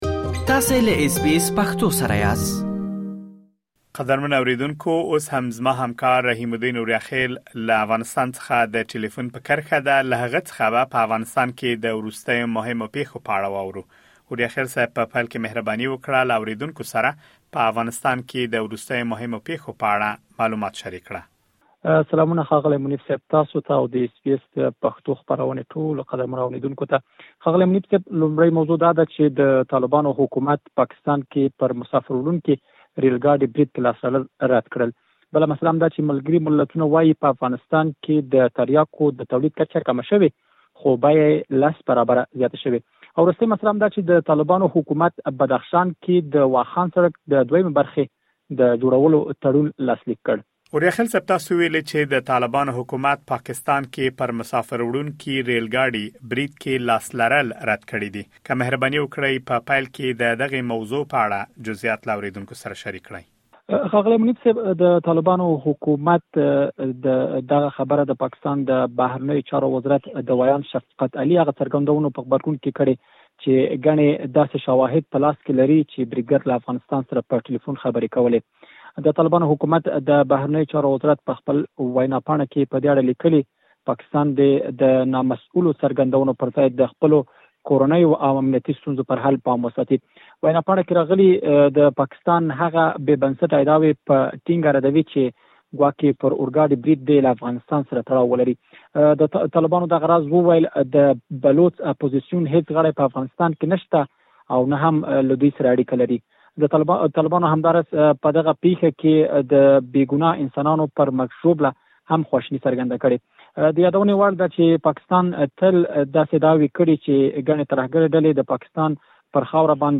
د افغانستان د وروستیو پېښو په اړه مهم معلومات په ترسره شوې مرکې کې اورېدلی شئ.